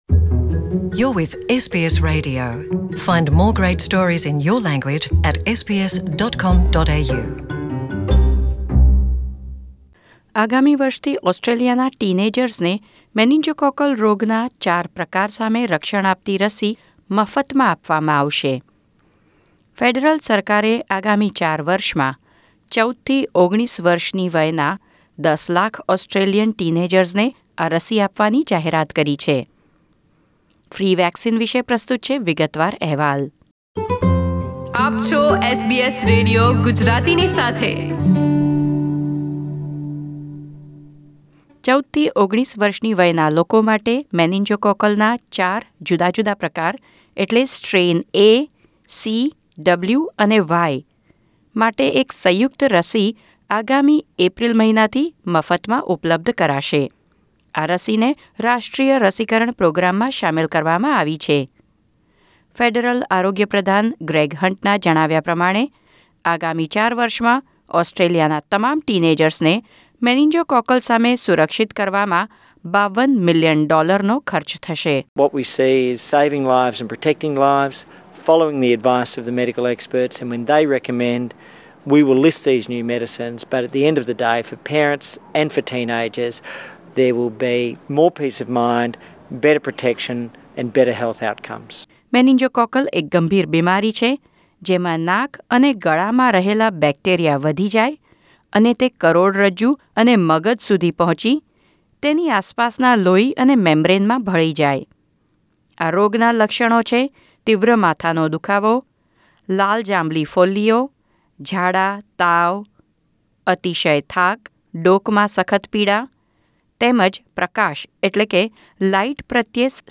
ઑસ્ટ્રેલિયાના ટીનેજર્સને મેનીંગોકૉકલ રોગના ચાર પ્રકારો સામે રક્ષણ આપતી રસી મફતમાં આપવામાં આવશે. ફ્રી વેક્સીનેશન વિષે પ્રસ્તુત છે વિગતવાર અહેવાલ.